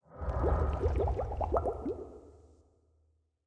whirlpool_ambient3.ogg